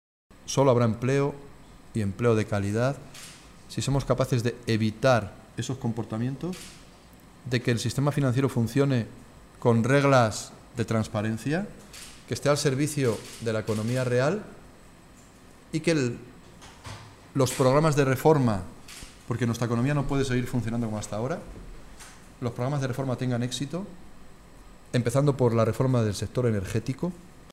Caldera que compareció en rueda de prensa antes de presentar en Toledo su libro “Tiempo para la Igualdad”, señaló que se han tenido que tomar medidas de ajuste muy importantes en nuestro país, “pero ahora que las cosas han empezado a serenarse, hay que actuar con contundencia a favor de los que más han pagado la crisis y me alegro mucho que mañana se apruebe esta medida porque la culpa de que muchos ciudadanos no puedan pagar su hipoteca no la tienen ellos sino la tremenda crisis que ellos no han provocado”.